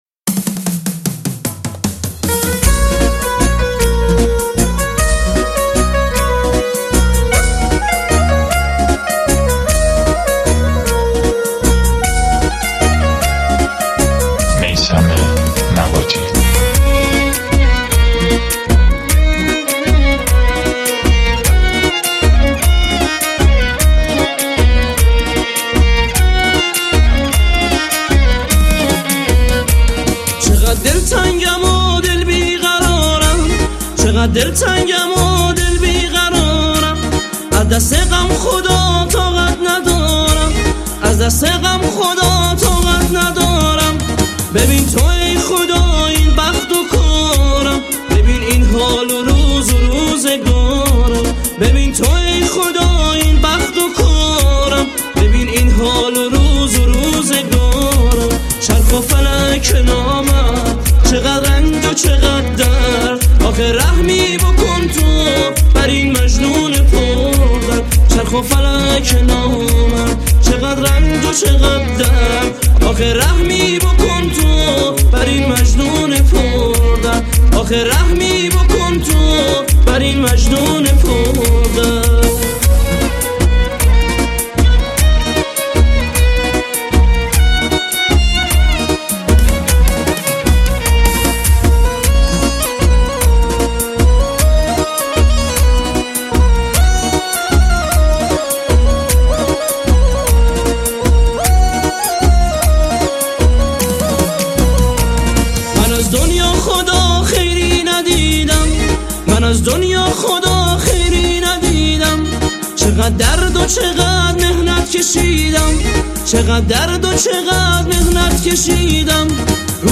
آهنگ مشهدی
Mahalli